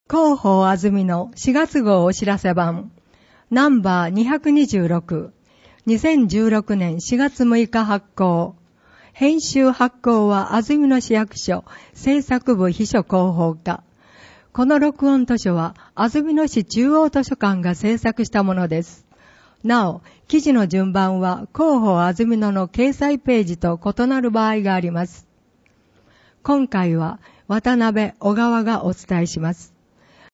「広報あづみの」を音声でご利用いただけます。この録音図書は、安曇野市中央図書館が制作しています。